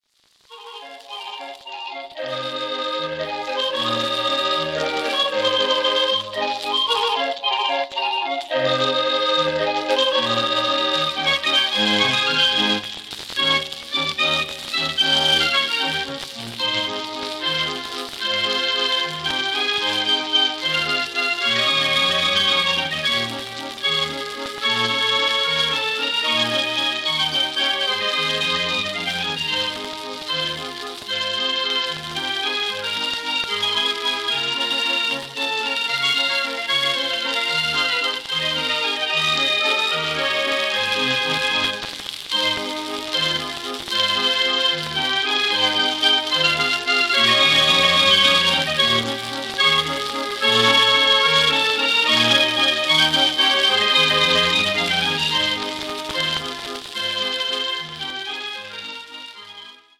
Formaat 78 toerenplaat, schellak